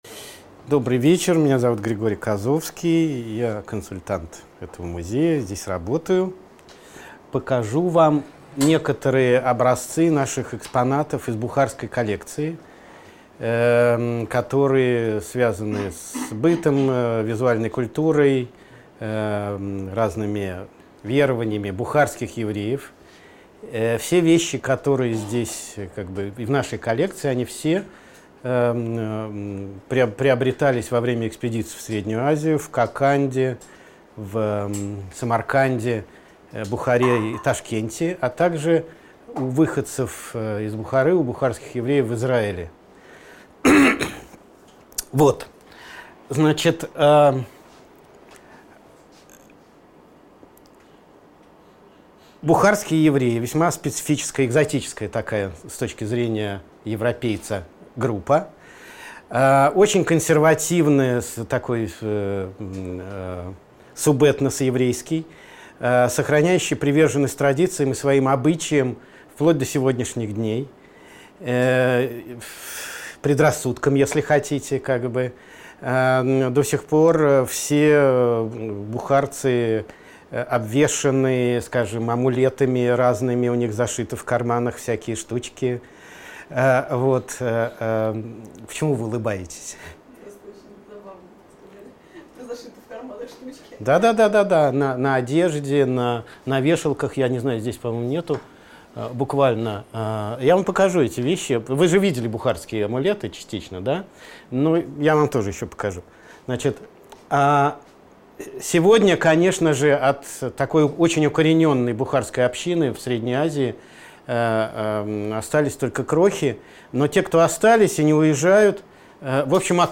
Аудиокнига Бухарская коллекция Музея истории евреев | Библиотека аудиокниг